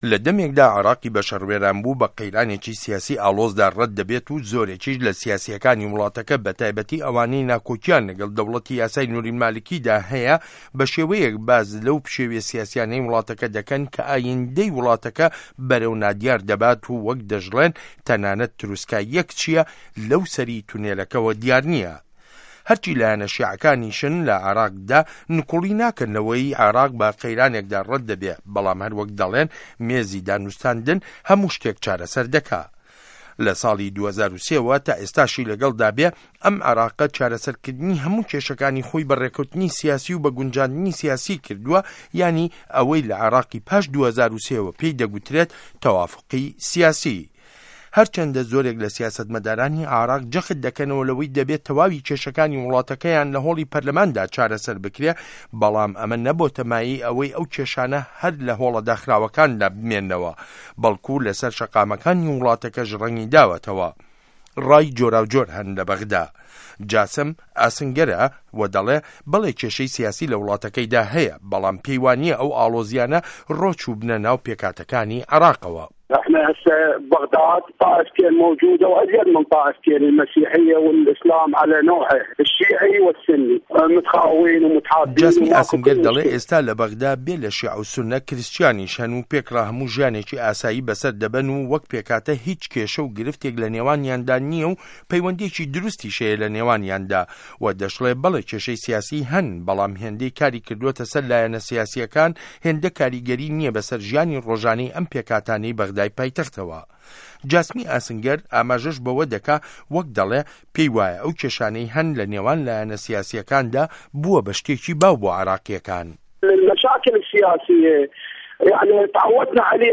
ڕاپۆرت له‌سه‌ر ڕای دانیشتوانی به‌غدا